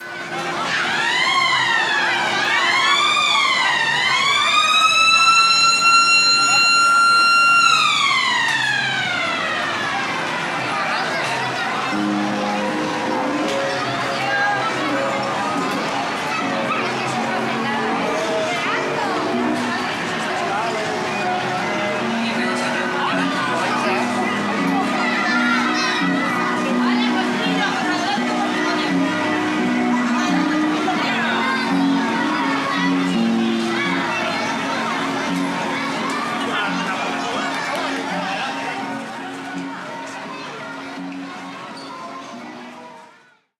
Parque de atracciones: carrusel